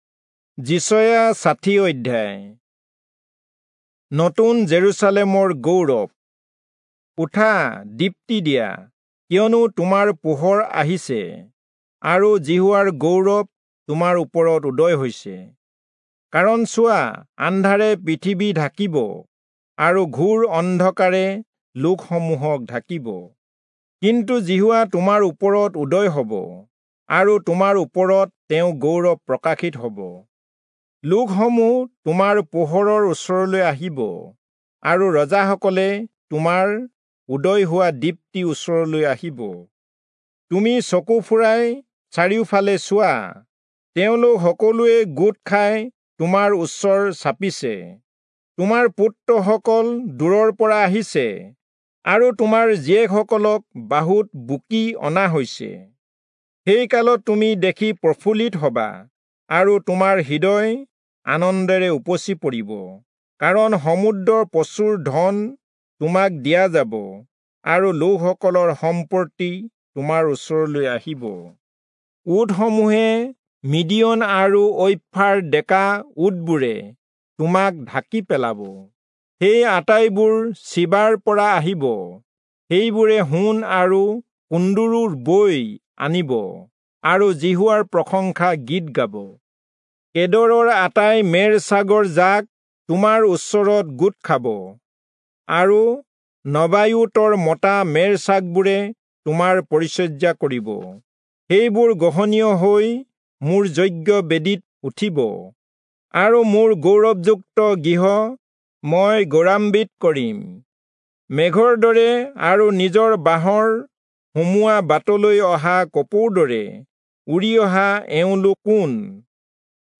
Assamese Audio Bible - Isaiah 15 in Mkjv bible version